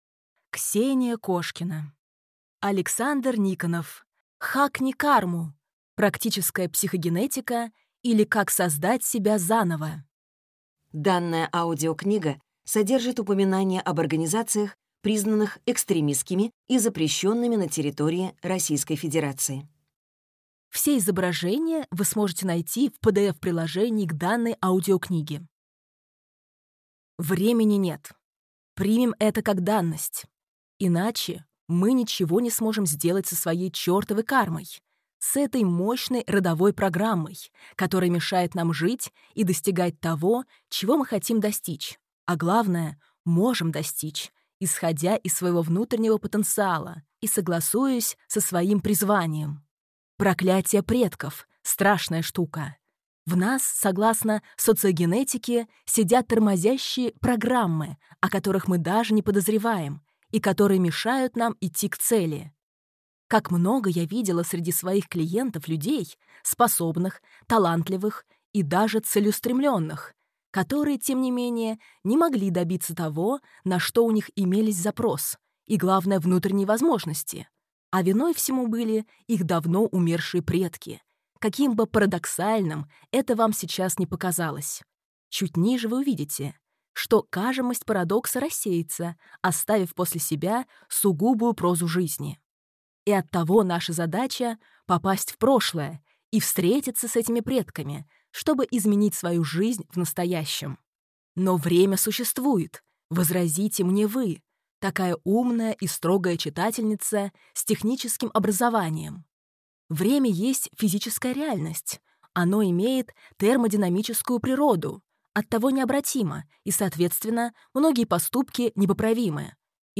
Аудиокнига Хакни Карму: практическая психогенетика, или как создать себя заново | Библиотека аудиокниг